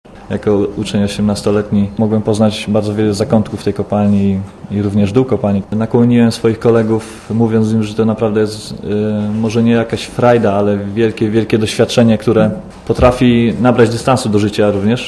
Mówi Jerzy Dudek